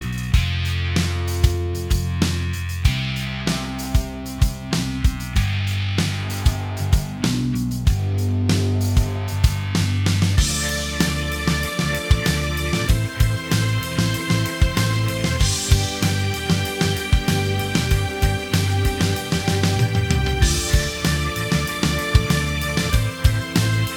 Minus Acoustic Guitar Indie / Alternative 4:47 Buy £1.50